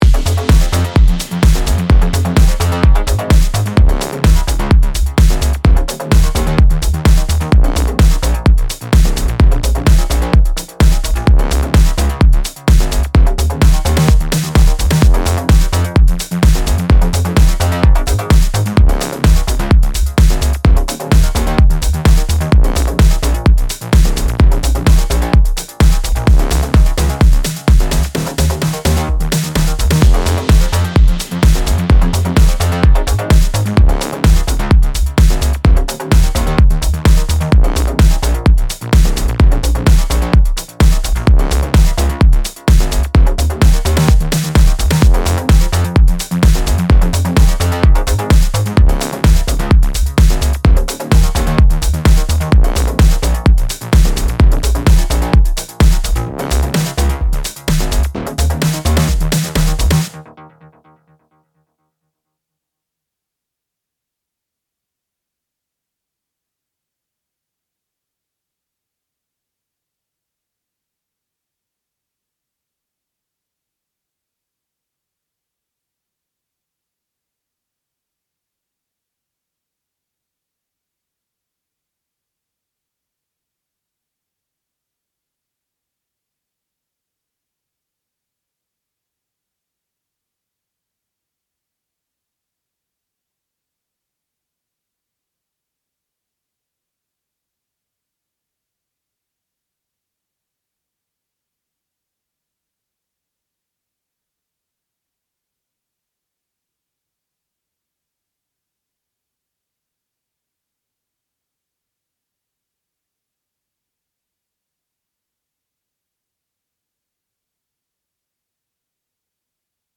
Progressive House
Теперь бочка с басом отдельно?